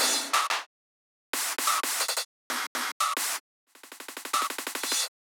• 10 Rhythmic Loops: Infuse your music with groove and energy using versatile rhythmic loops that drive your tracks forward.
3-180-Snare-Loop.wav